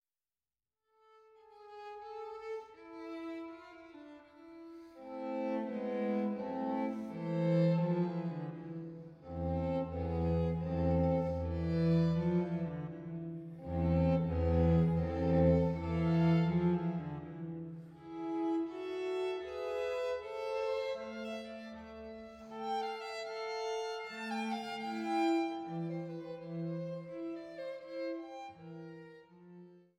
Violine
Violincello